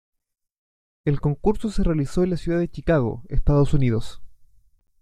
con‧cur‧so
Pronounced as (IPA)
/konˈkuɾso/